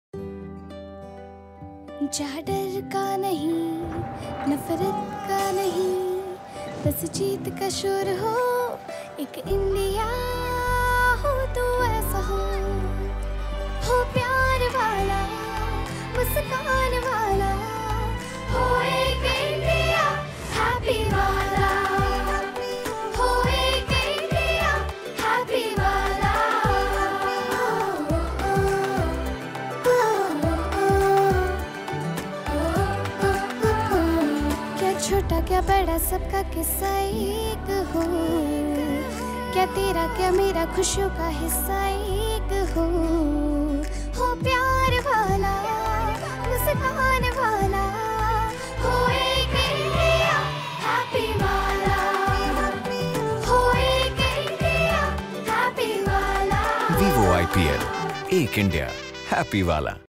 TV ADS Tone